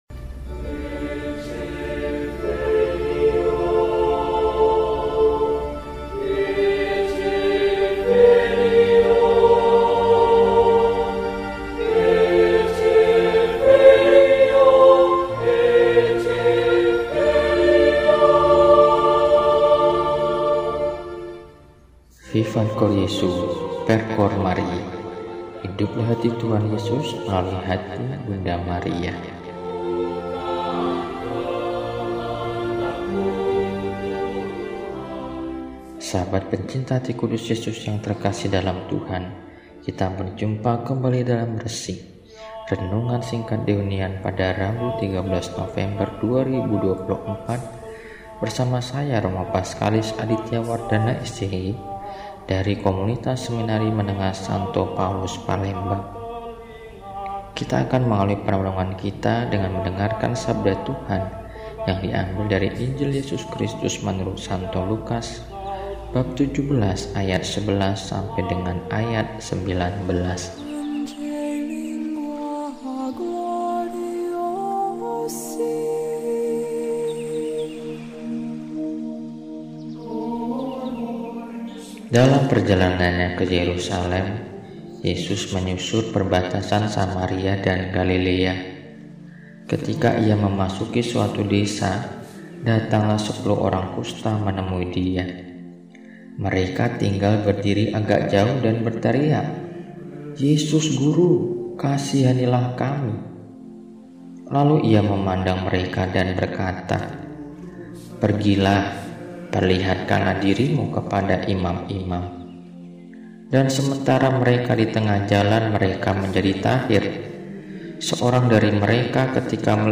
Rabu, 13 November 2024 Hari Biasa Pekan XXXII – RESI (Renungan Singkat) DEHONIAN